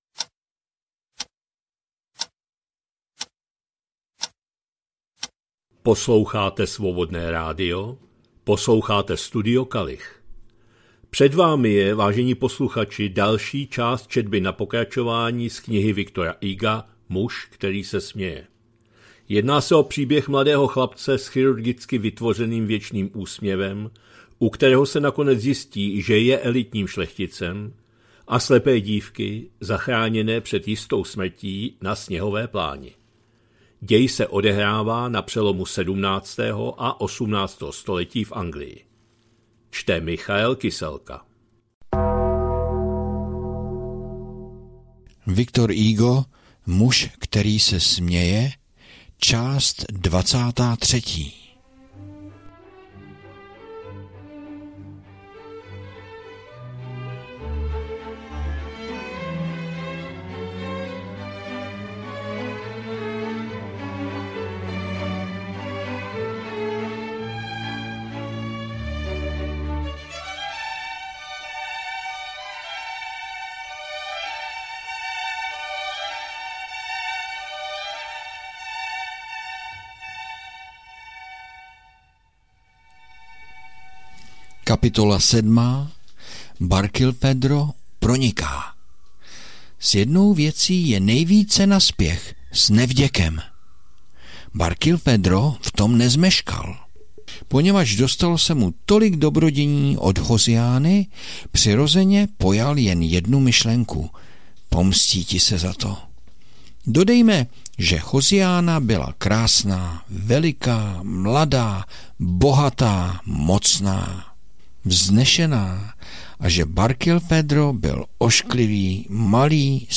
2025-08-11 – Studio Kalich – Muž který se směje, V. Hugo, část 23., četba na pokračování